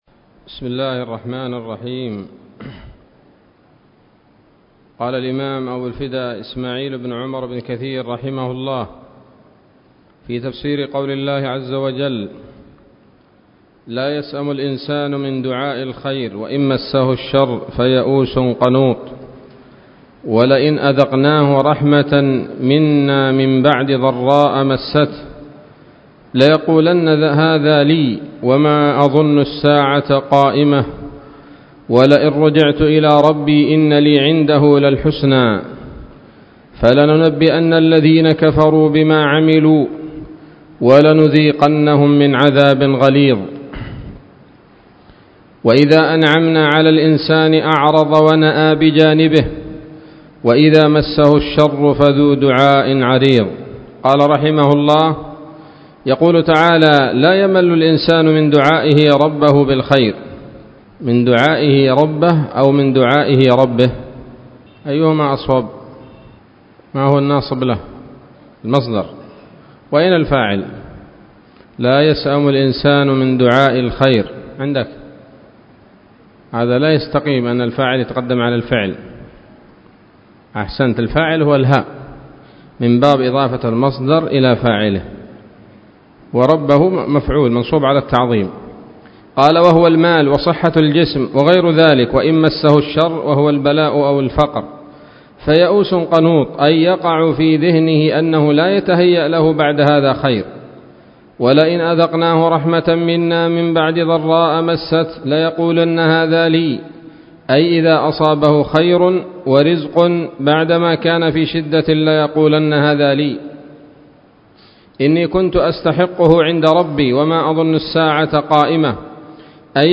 الدرس الثاني عشر وهو الأخير من سورة فصلت من تفسير ابن كثير رحمه الله تعالى